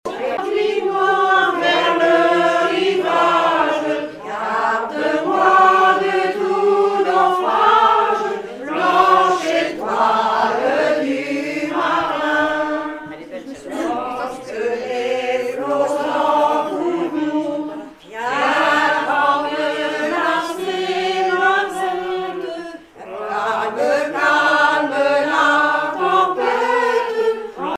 Club d'anciens de Saint-Pierre association
cantique
Pièce musicale inédite